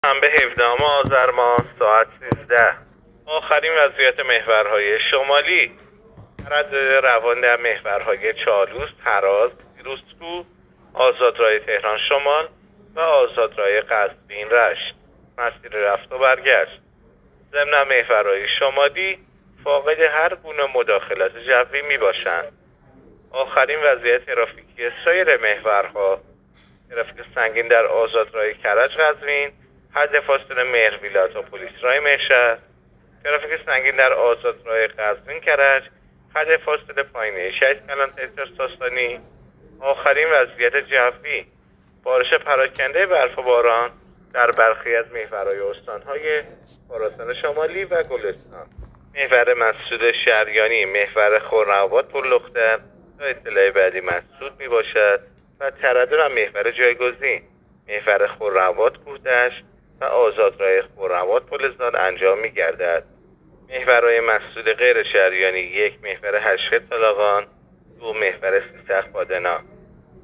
گزارش رادیو اینترنتی از آخرین وضعیت ترافیکی جاده‌ها تا ساعت ۱۳ هفدهم آذر؛